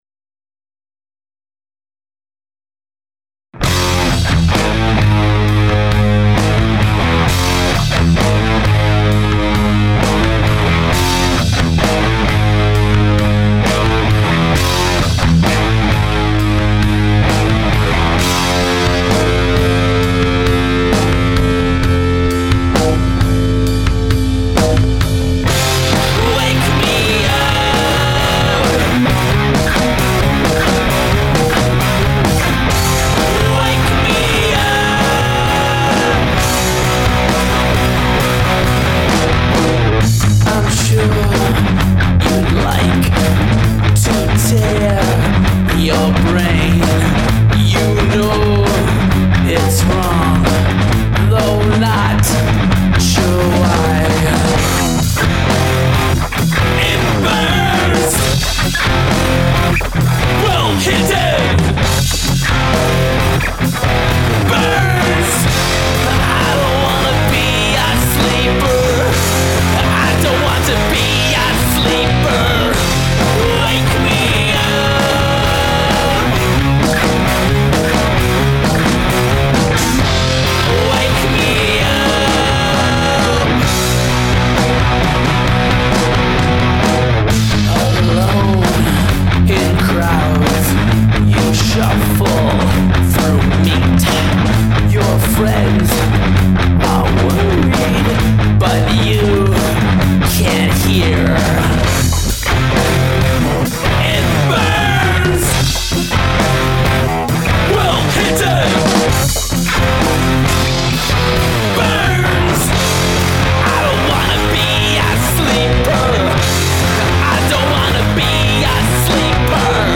Rawk.
But this is awesome within that metal genre of which I am not a fan.